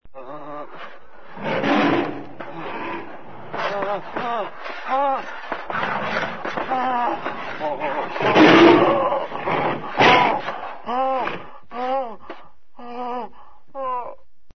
TERROR - Tono movil - EFECTOS DE SONIDO
Tonos gratis para tu telefono – NUEVOS EFECTOS DE SONIDO DE AMBIENTE de TERROR
Terror.mp3